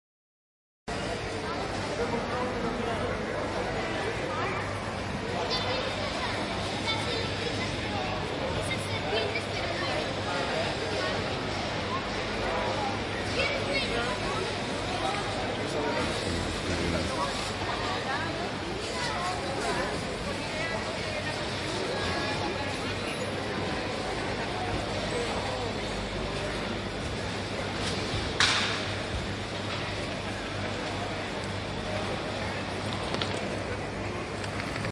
Mercado central Valencia: Efectos de sonido negocios
La versatilidad de este sonido radica en su capacidad para evocar la mezcla de sonidos de compradores, vendedores, productos frescos y el ambiente arquitectónico único de un mercado central histórico.
Tipo: sound_effect
Mercado central Valencia.mp3